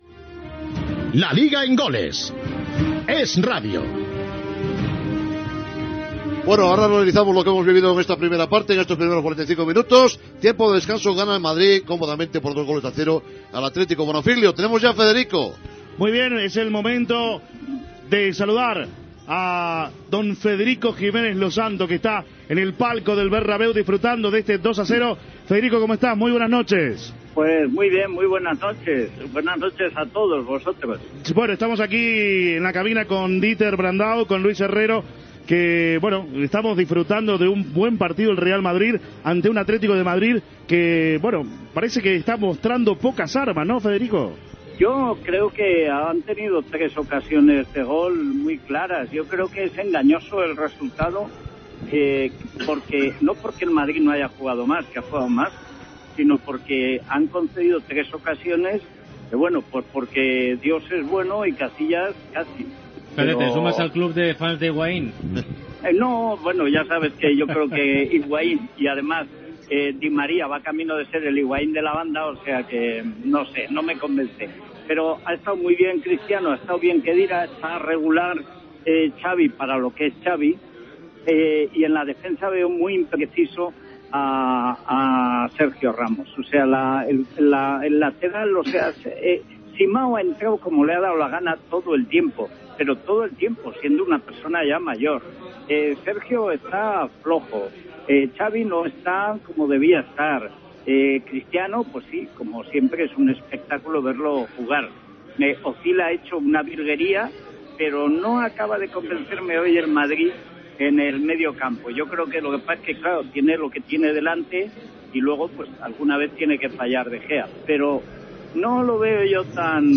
Comentaris a la mitja part del partit de fútbol masculí entre el Real Madrid i l'Atlértico de Madrid.
Esportiu